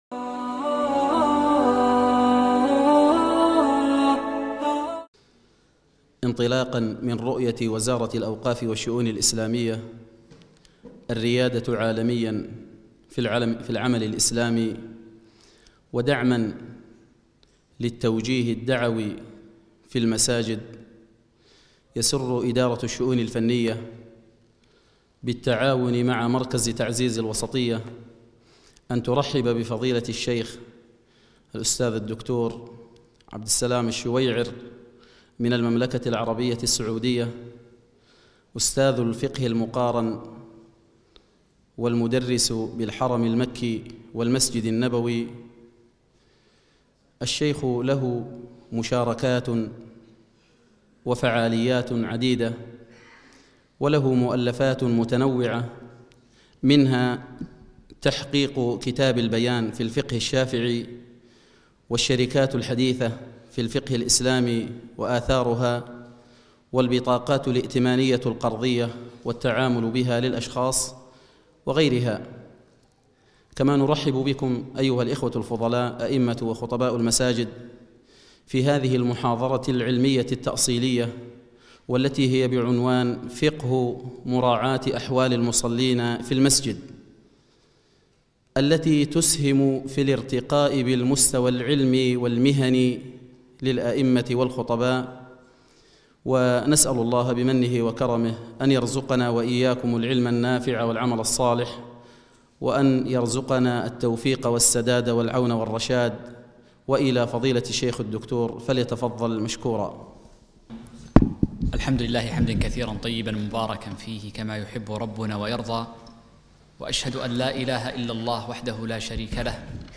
محاضرة - فقه مراعاة المصلين في المساجد